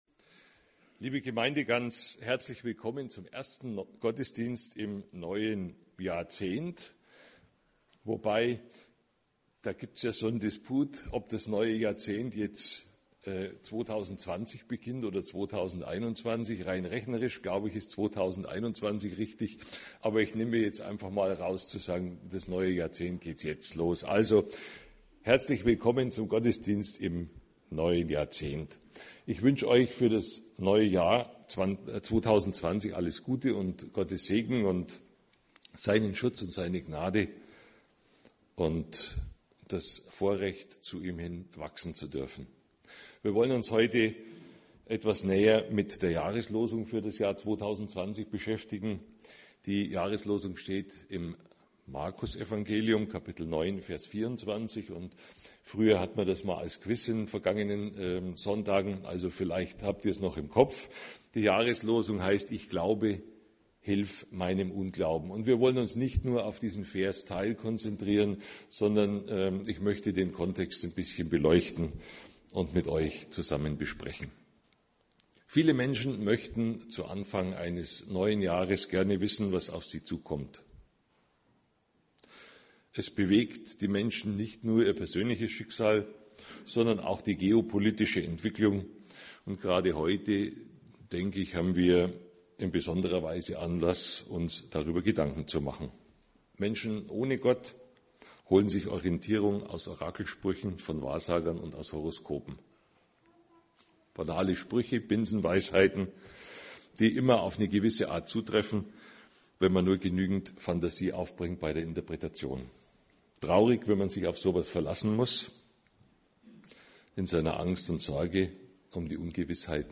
predigte